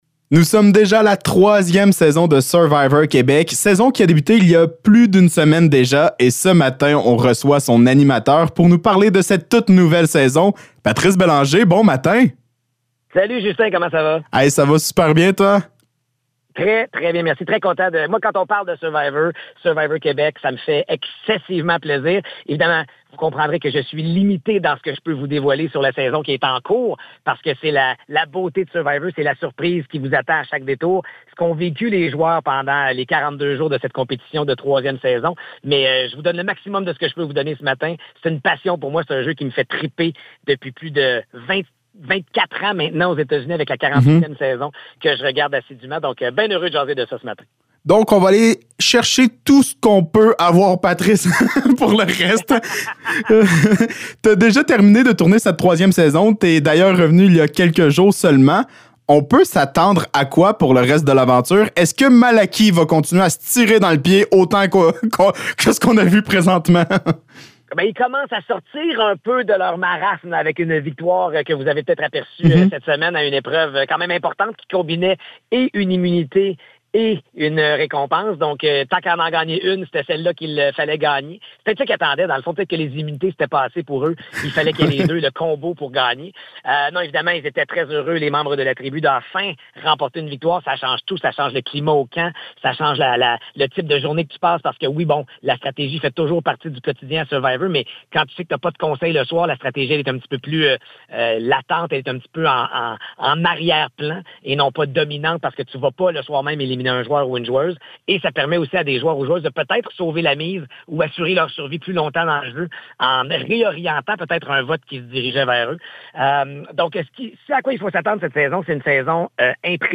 Entrevue avec Patrice Bélanger